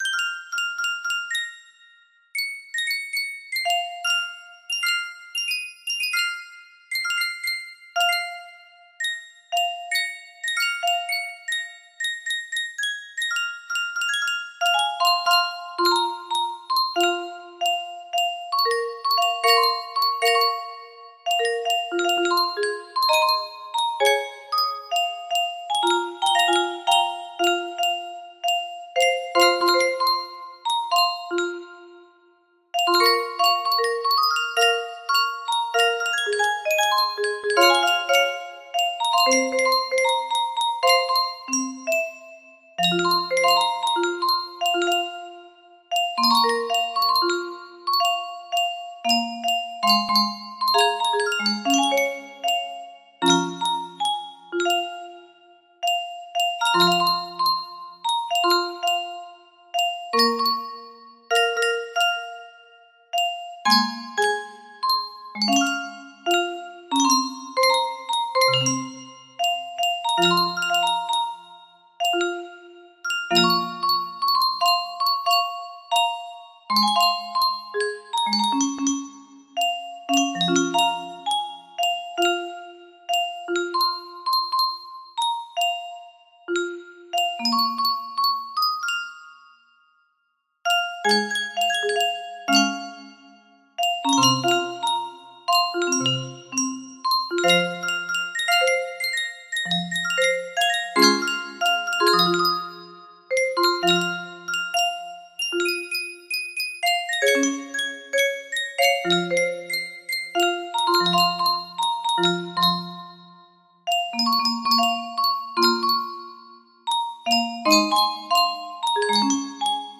Emerald City's Fall music box melody
Full range 60